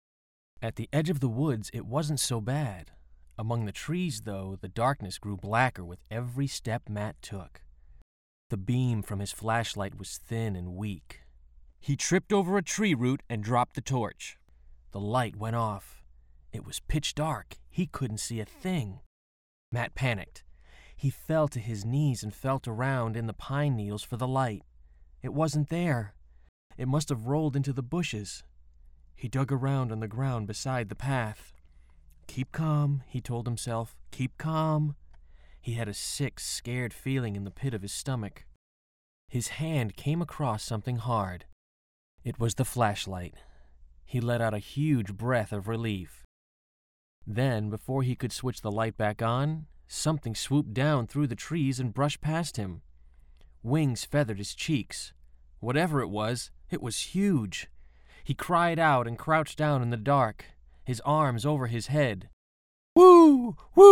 Audio Book Demos
Male Voice Over Talent